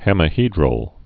(hĕmĭ-hēdrəl)